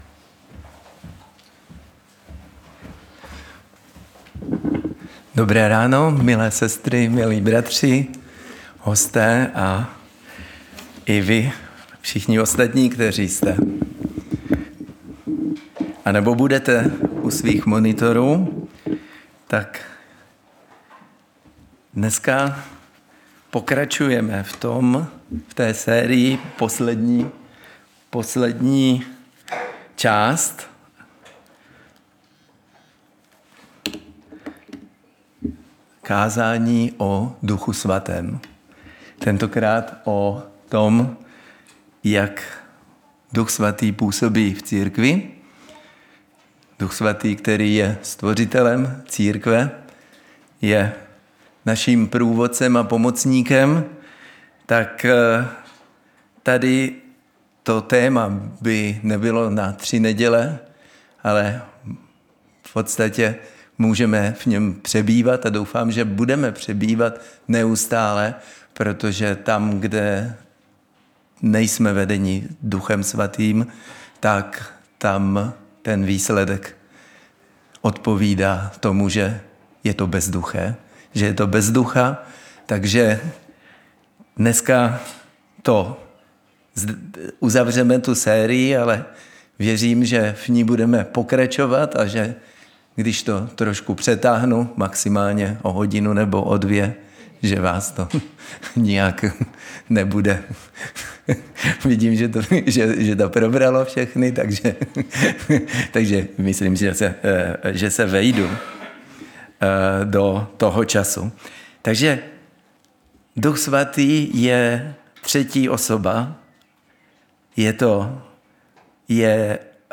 Nedělní vyučování